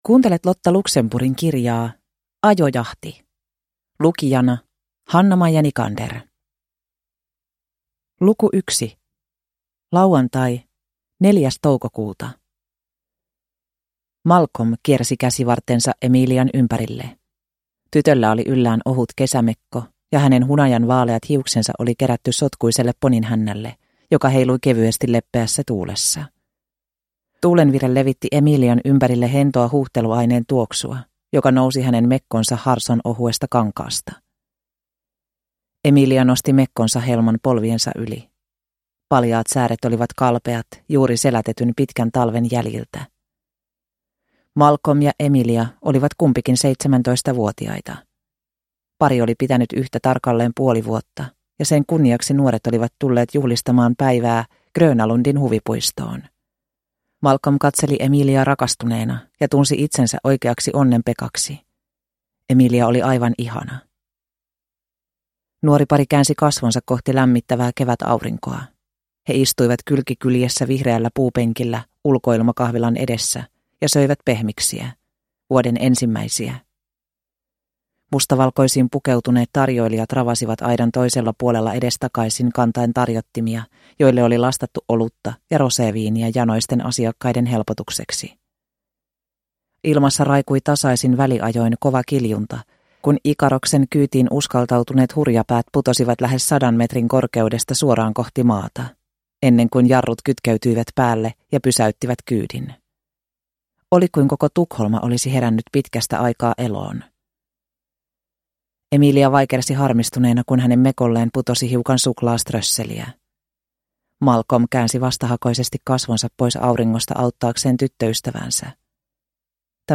Ajojahti – Ljudbok
Deckare & spänning Njut av en bra bok